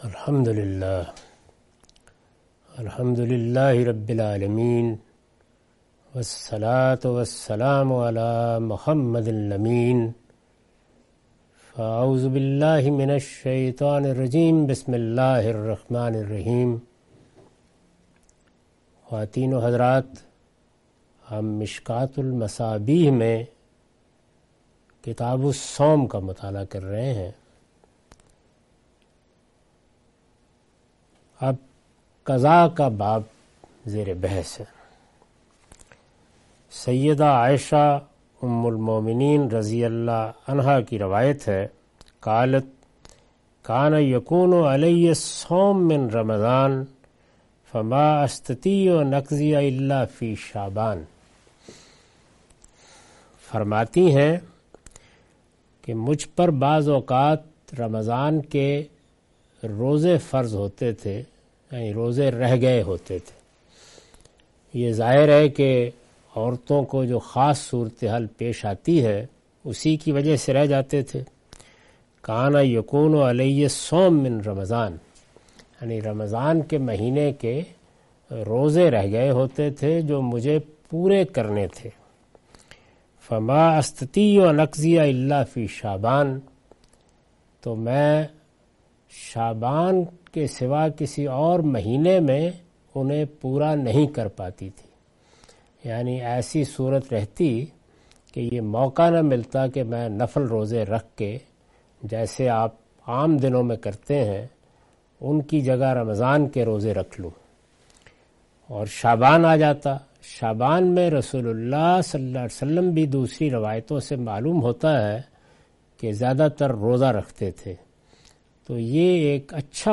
Sayings of the Prophet , Questions & Answers